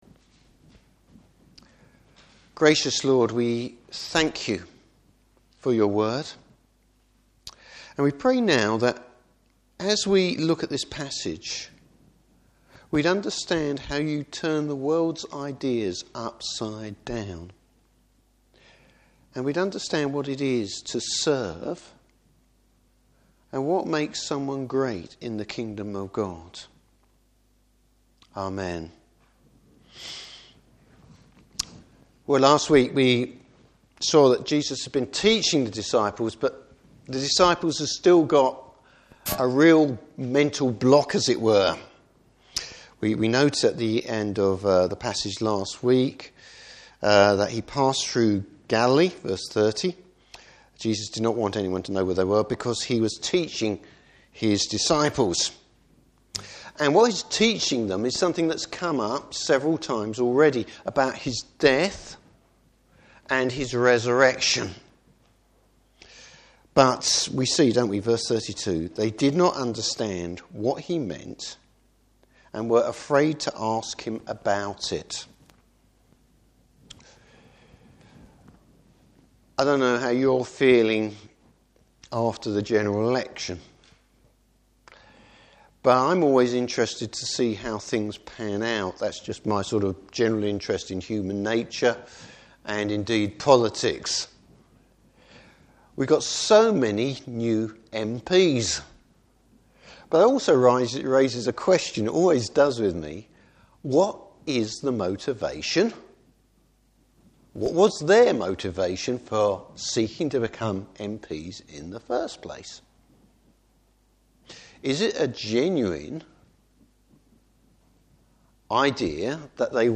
Service Type: Morning Service What true greatness looks like.